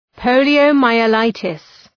Προφορά
{,pəʋlıəʋ,maıə’laıtıs}